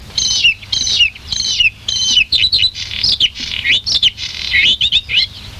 Gorgebleue à miroir
Luscinia svecica
gorgebleue.mp3